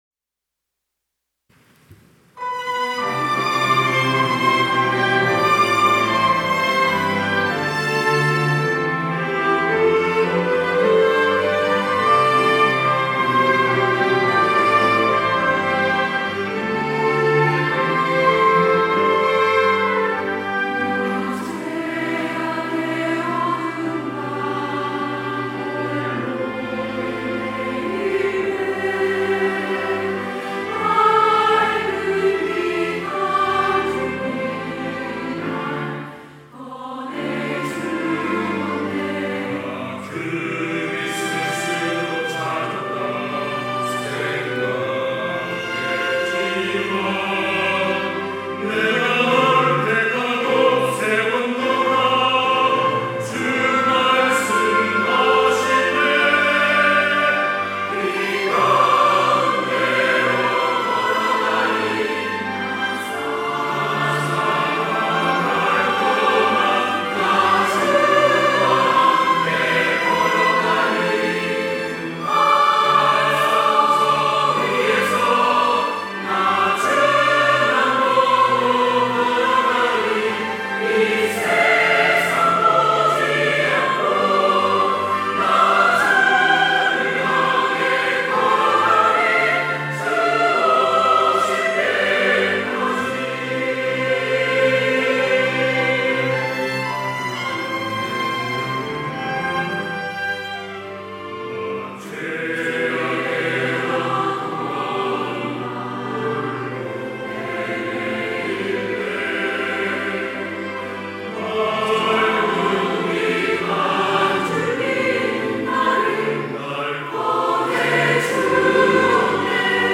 호산나(주일3부) - 주를 향해 걸어가리
찬양대